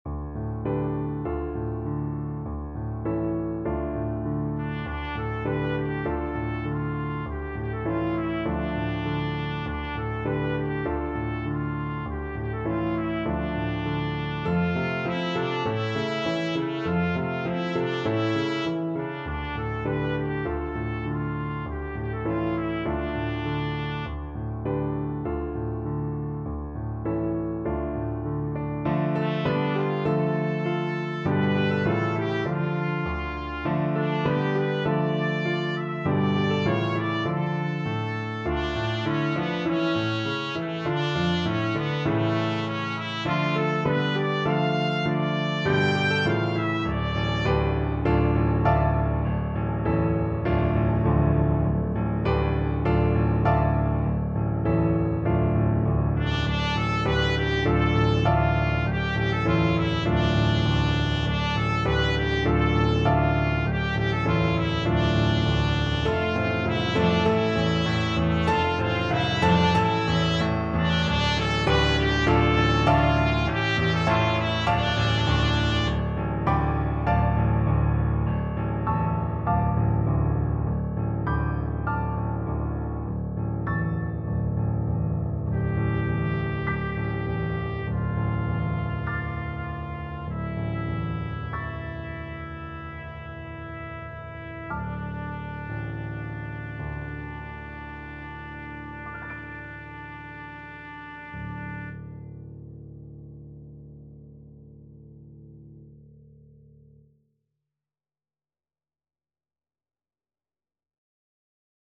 Play (or use space bar on your keyboard) Pause Music Playalong - Piano Accompaniment Playalong Band Accompaniment not yet available transpose reset tempo print settings full screen
2/4 (View more 2/4 Music)
D minor (Sounding Pitch) E minor (Trumpet in Bb) (View more D minor Music for Trumpet )
Moderato
Trumpet  (View more Easy Trumpet Music)
Traditional (View more Traditional Trumpet Music)
sing_we_now_TPT.mp3